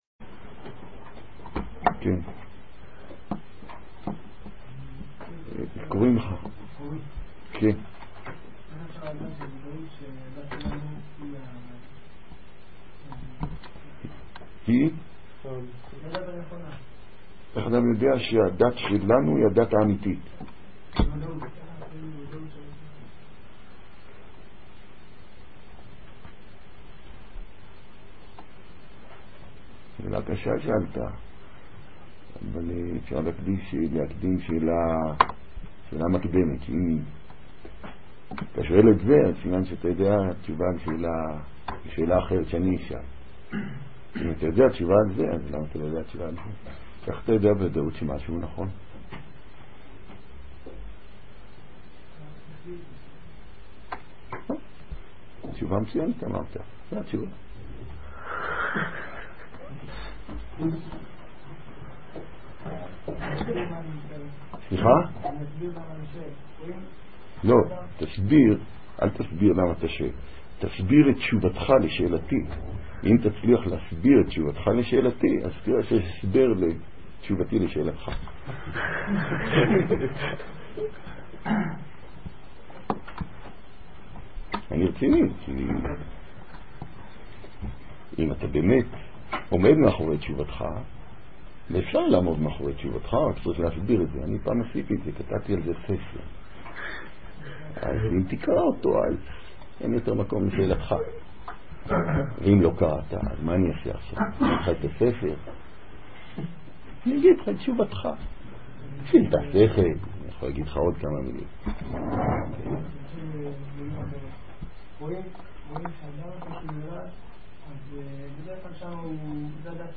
מתוך שו"ת. ניתן לשלוח שאלות בדוא"ל לרב